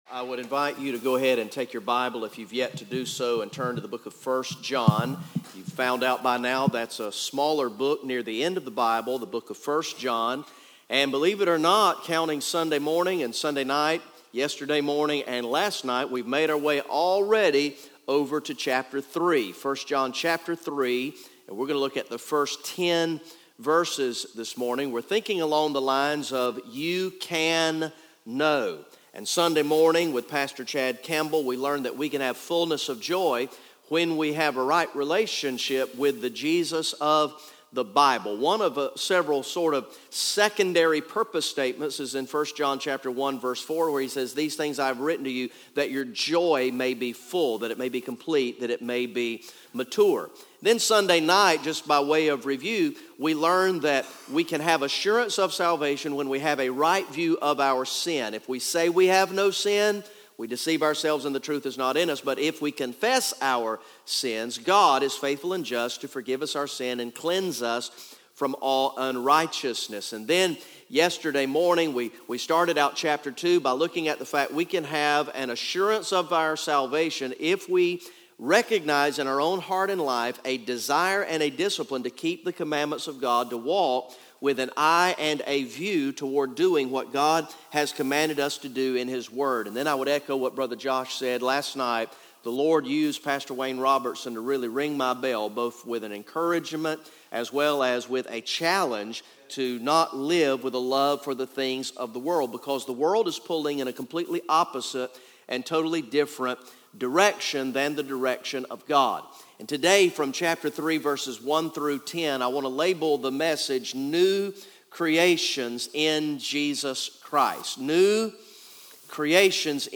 Message #05 from the ESM Summer Camp sermon series through the book of First John entitled "You Can Know"